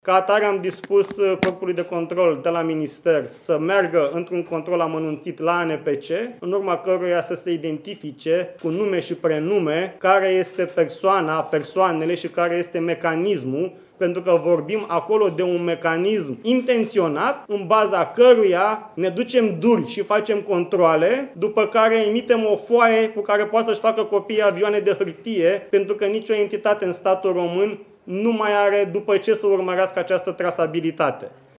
În total, statul român a pierdut aproape 16 milioane de lei după ce amenzile au rămas neplătite, a anunțat, la o conferință de presă, ministrul Radu Miruță.